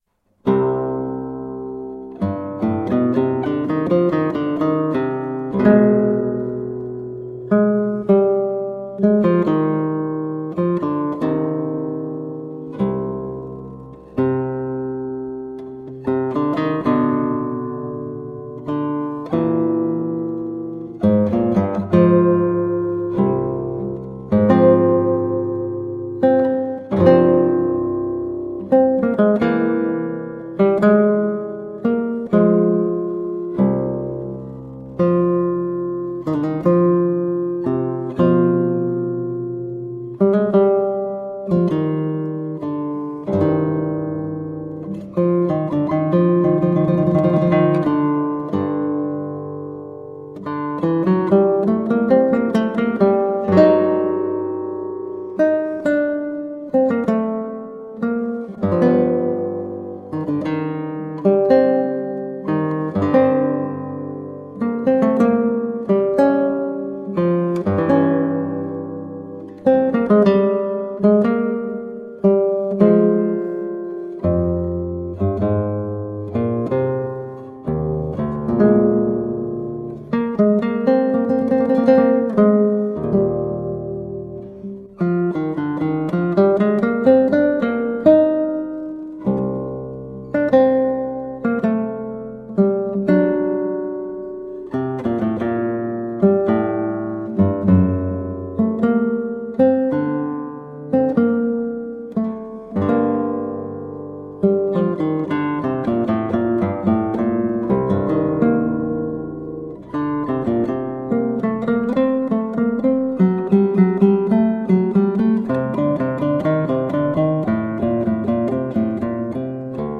Colorful classical guitar.
Classical, Baroque, Instrumental
Classical Guitar